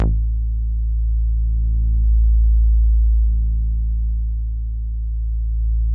WAVEBASS  A2.wav